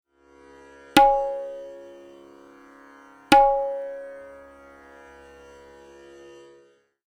(Bols below: recorded on my tabla)
—Dayan strokes (treble drum)—
• Na: Index finger strikes the edge of the maidan with force, producing a ringing, sustained tone (tuned to the raga’s Sa) – as the 3rd/4th fingers lightly mute the inner circle to control resonance patterns.
TALAS-Bol-Syllable-Na.mp3